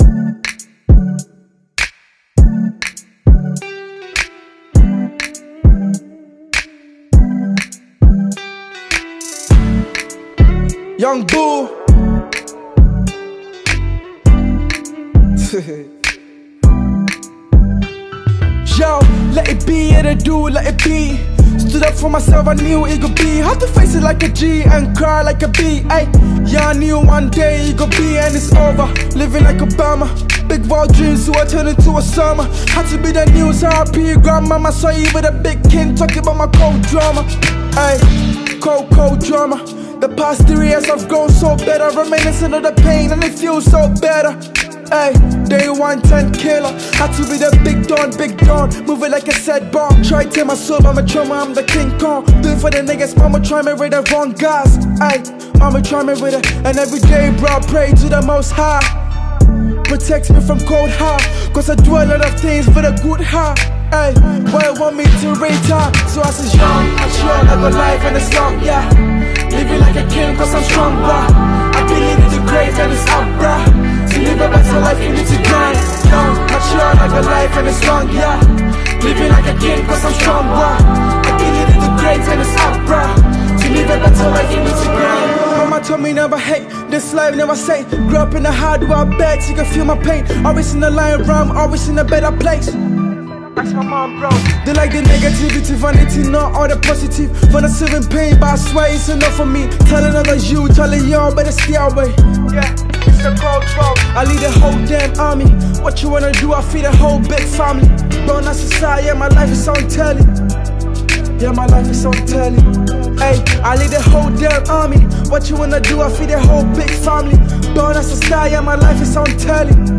Prominent Ghanaian rapper